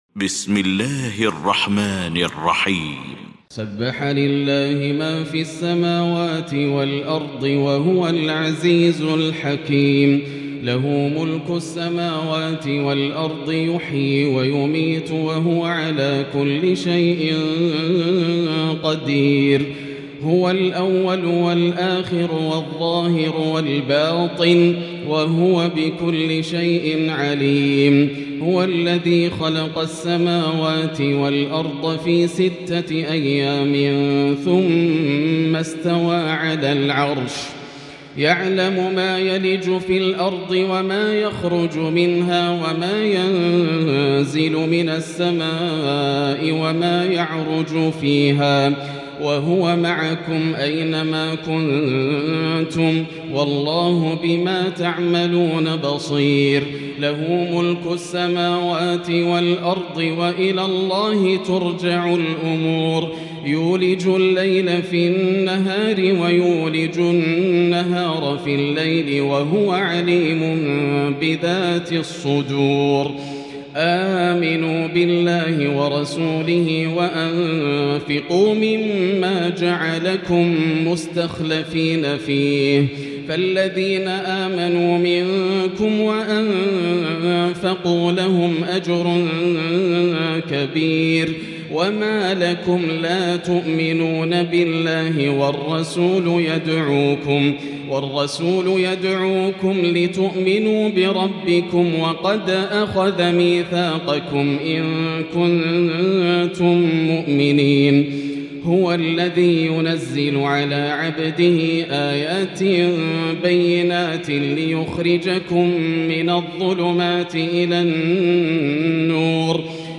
المكان: المسجد الحرام الشيخ: فضيلة الشيخ ياسر الدوسري فضيلة الشيخ ياسر الدوسري الحديد